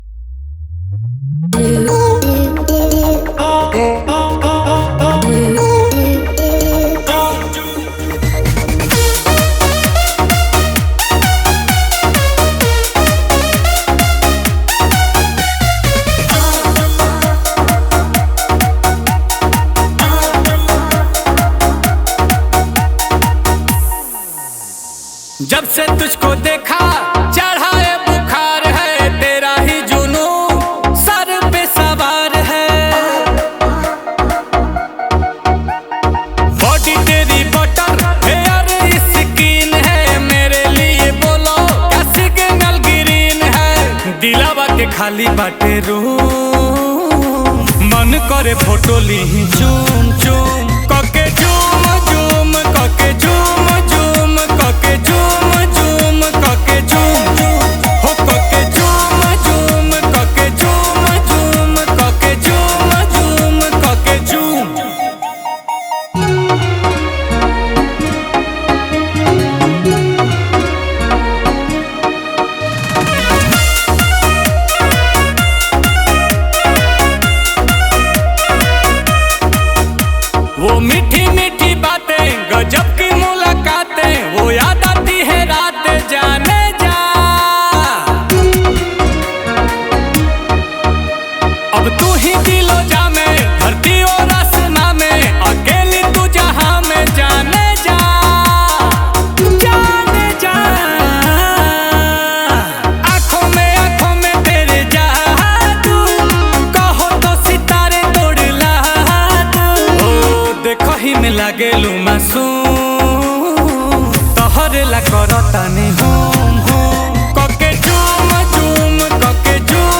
Releted Files Of New Bhojpuri Song 2025 Mp3 Download